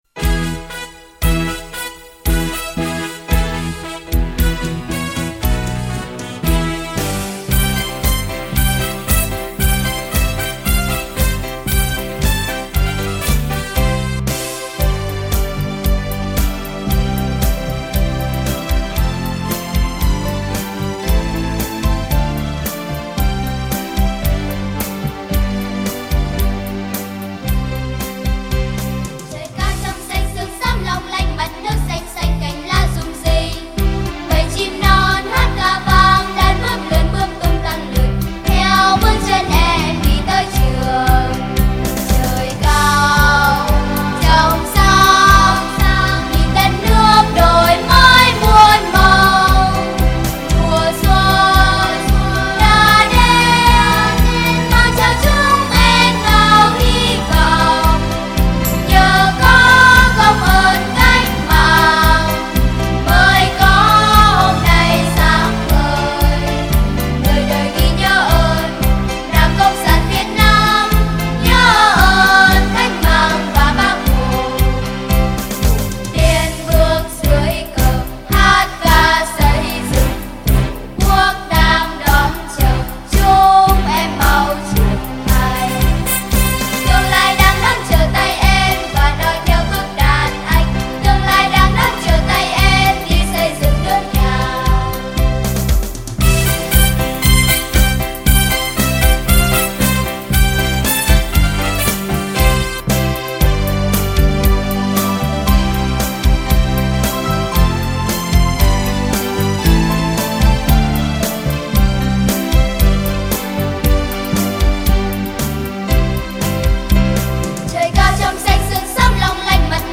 Album: Épica, gran coral, Sinfonía con coro